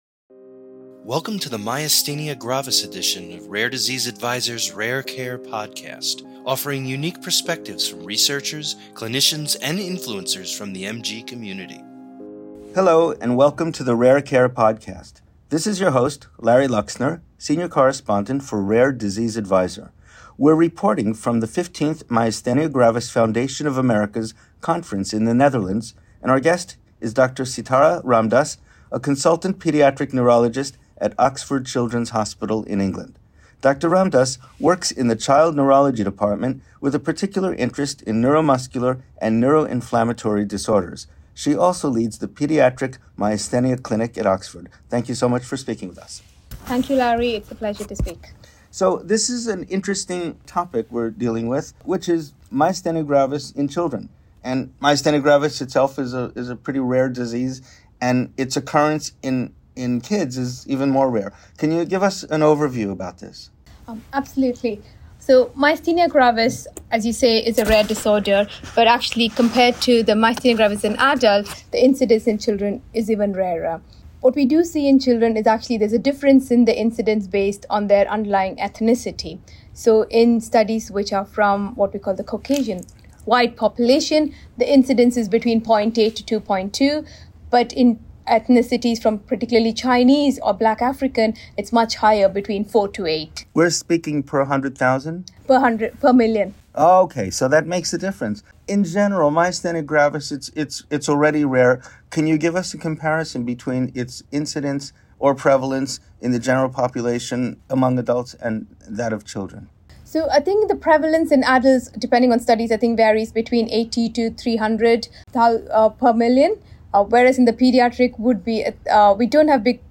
Rare Care Podcast / An Interview